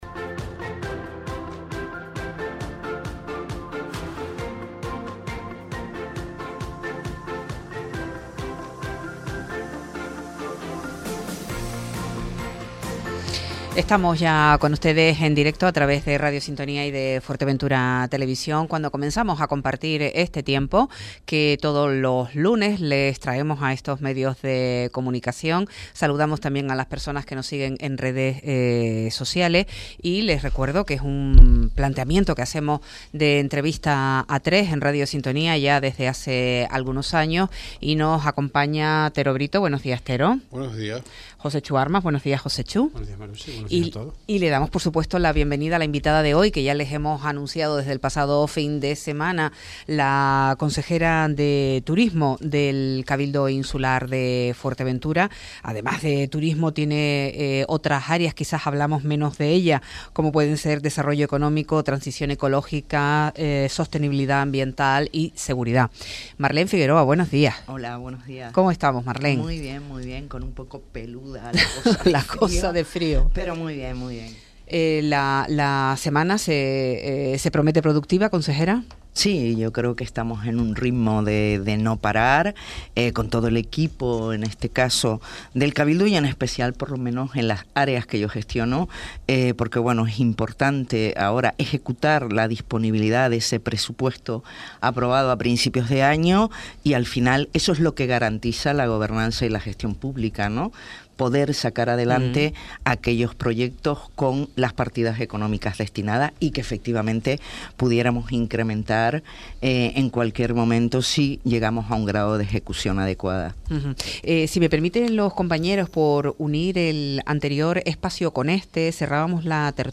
En Análisis recibimos a la consejera de Área Insular de Turismo, Desarrollo Económico, Transición Ecológica, Sostenibilidad Ambiental y Seguridad con la que hablamos sobre la actualidad del sector turístico.